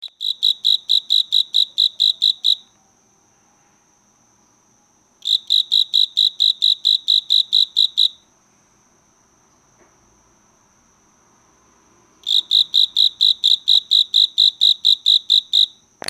人家周辺に普通で，ちょっとした草地があれば，あらゆる場所で鳴いているということです。主に明け方に鳴き，「りー，りー，りー」と聞こえるということですが，いかがでしょうか（ 鳴き声♬ ）。
tuduresasekoorogi5.MP3